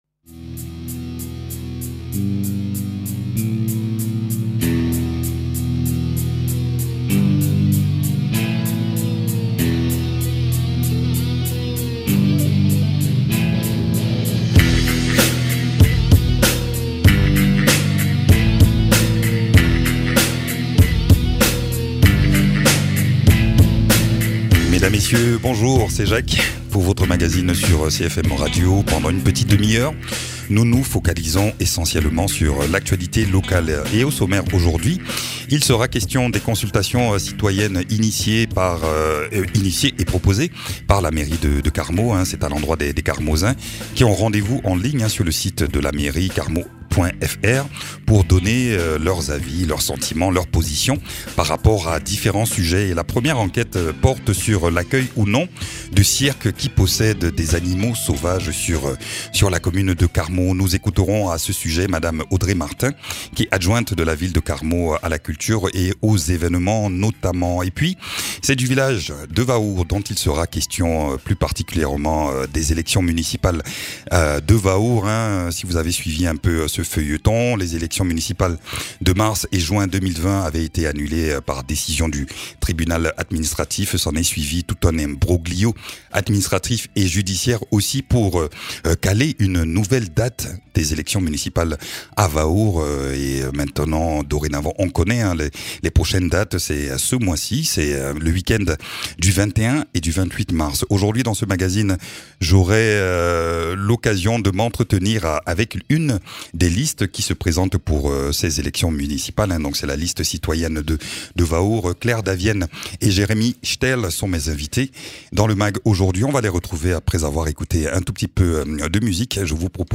Audrey Martin, élu de la ville de Carmaux chargée notamment des événements et de la culture.